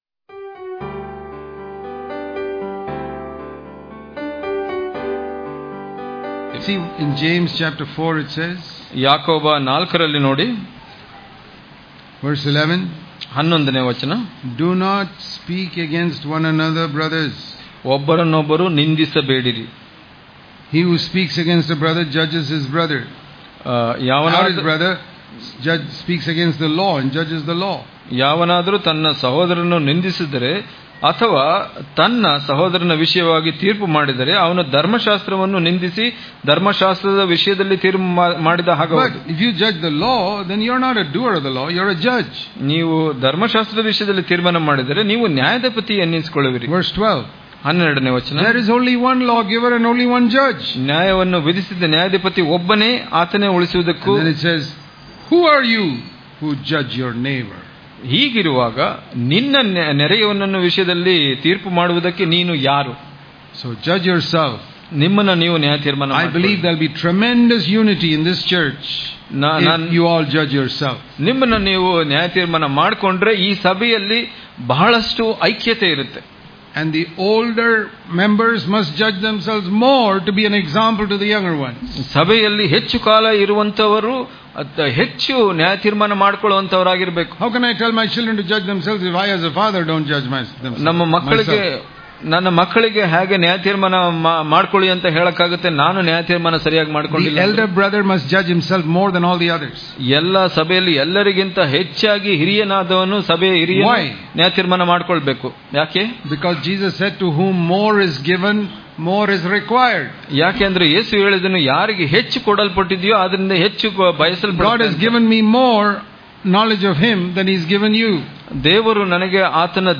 Daily Devotions